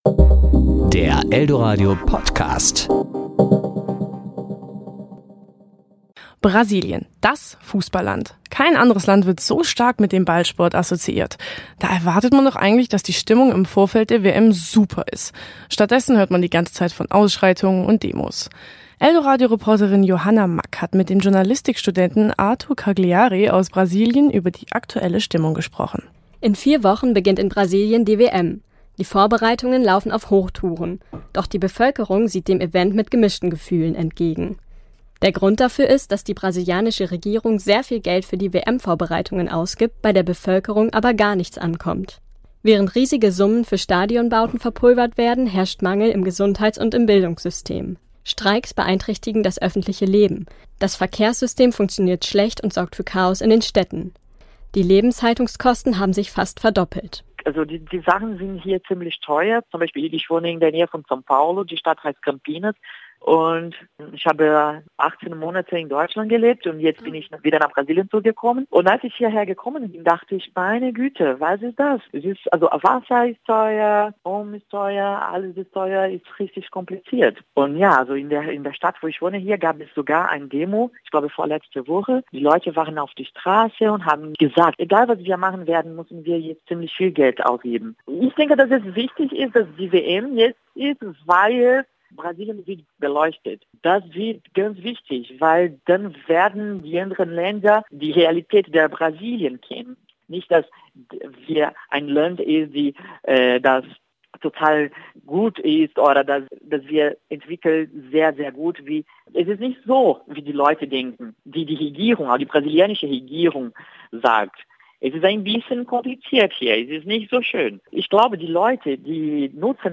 Serie: Wort